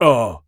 Male_Grunt_Hit_Neutral_03.wav